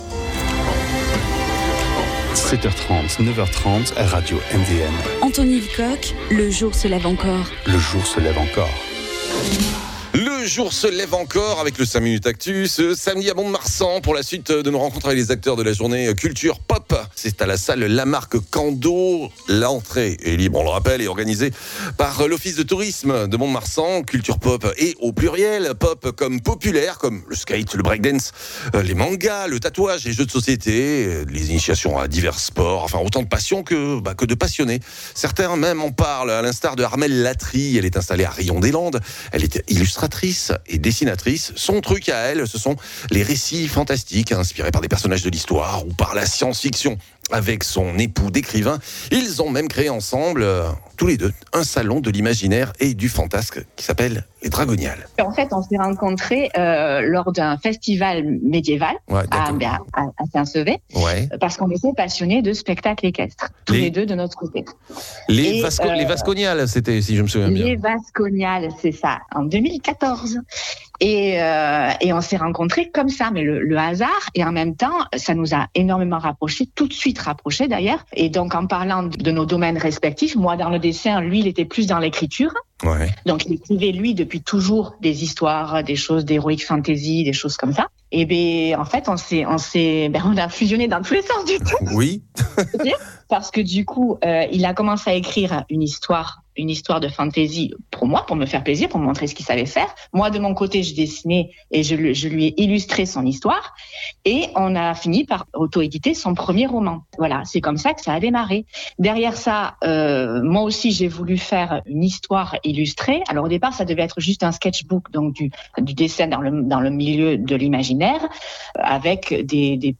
La Rionnaise donnera une conférence à midi sur comment faire de sa passion créatrice son métier. Entretien.